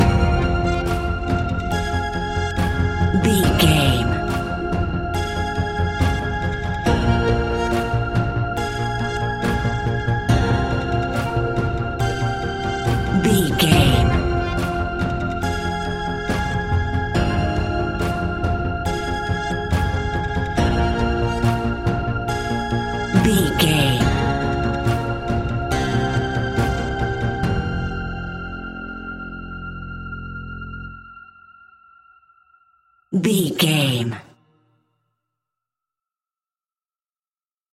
In-crescendo
Thriller
Aeolian/Minor
ominous
dark
eerie
synthesizer
instrumentals
Horror Pads
Horror Synths